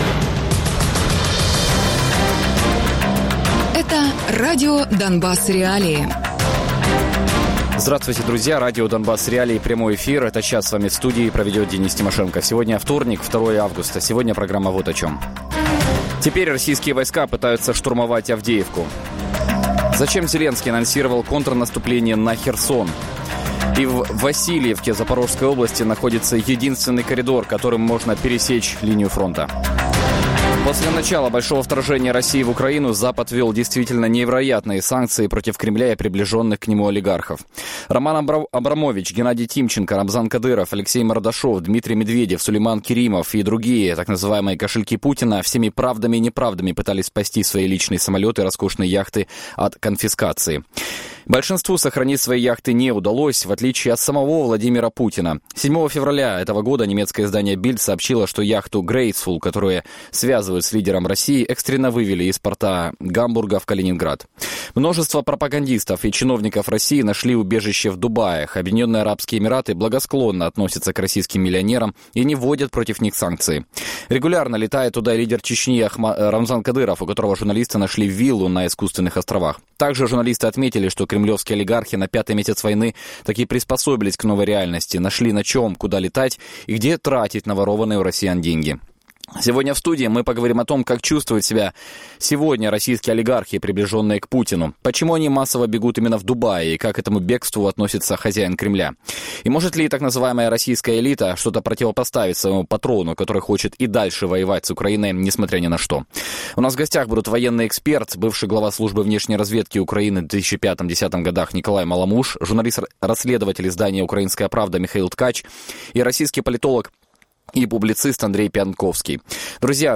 Микола Маломуж - військовий експерт, колишній голова Служби зовнішньої розвідки (2005 - 2010 рр.)
Андрій Піонтковський - російський політолог та публіцист